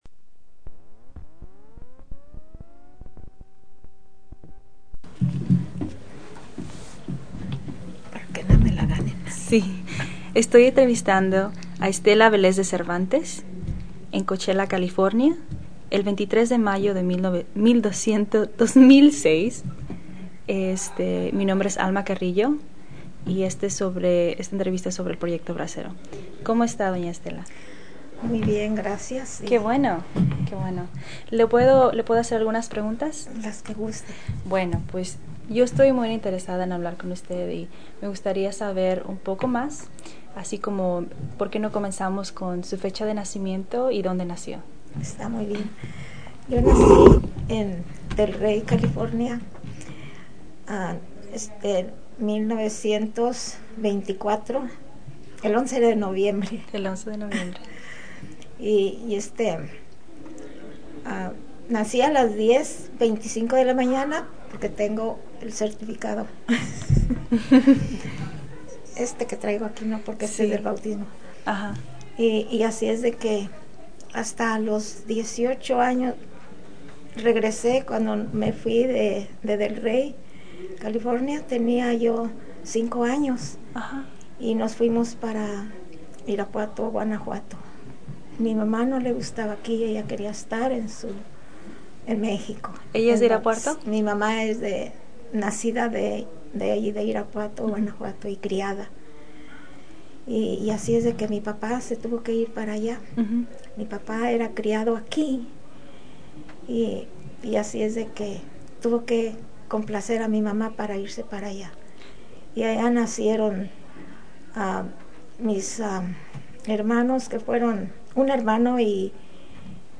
Summary of Interview